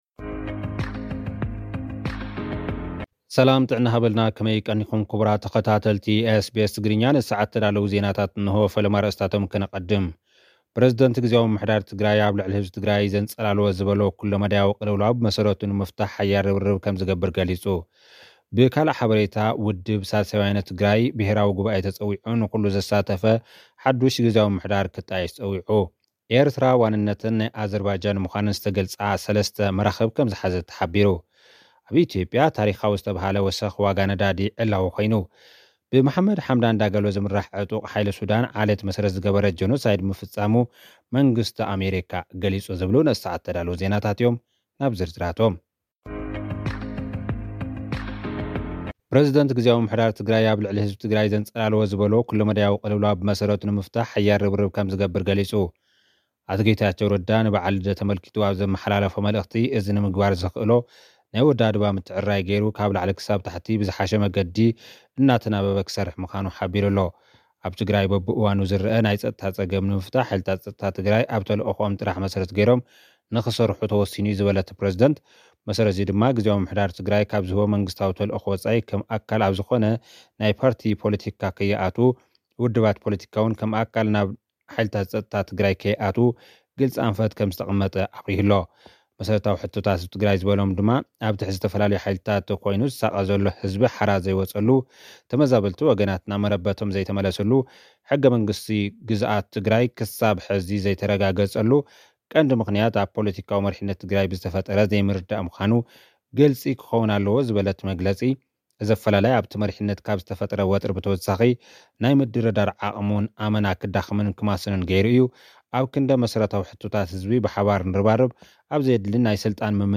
ልኡኽና ዝሰደደልና ጸብጻብ እዞም ዝስዕቡ ኣርእስታት ኣለዉዎ፡ ፕሬዚደንት ግዝያዊ ምምሕዳር ትግራይ ኣብ ልዕሊ ህዝቢ ትግራይ ዘንፀላለወ ኩለመዳያዊ ቅልውላው ብመሰረቱ ንምፍታሕ ሓያል ርብርብ ከም ዝግበር ገሊጹ። ኤርትራ ዋንነተን ናይ ኣዘርባጃን ምኳነን ዝተገልጻ ሰለስተ መራኽብ ከም ዝሓዘት ተገሊጹ። ኣብ ኢትዮጵያ ታሪኻዊ ወሰኽ ዋጋ ነዳዲ ዕላዊ ኮይኑ። ብመሓመድ ሓምዳን ዳጋሎ ዝምራሕ ዕጡቕ ሓይሊ ሱዳን ዓሌት መሰረት ዝገበረ ጀኖሳይድ ምፍፃሙ መንግስቲ ኣሜሪካ ዕላዊ ጌሩ።